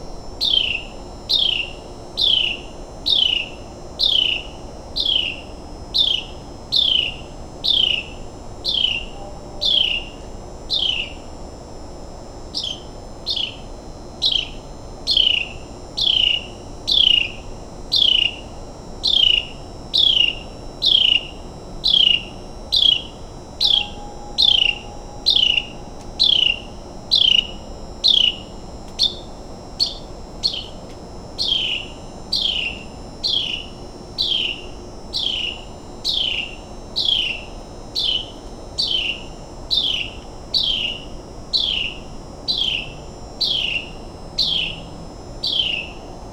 Here again are the first two songs for comparison.
Talk about song variety (and vocal agility).
carolina-wren-30621.wav